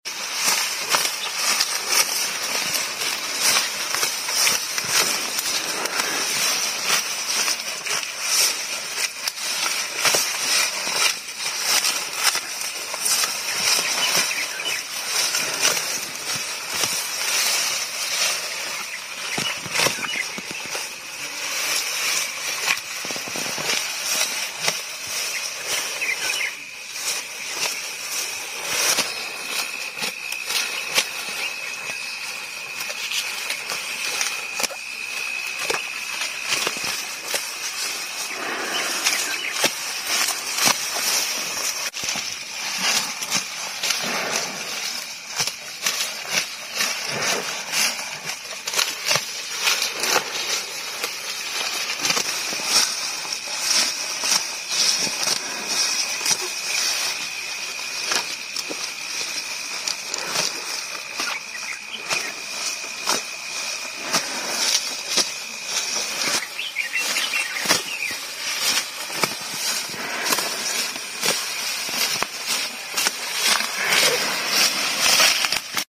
ASMR Sounds of a Grazing sound effects free download
ASMR Sounds of a Grazing Cow but Eating Different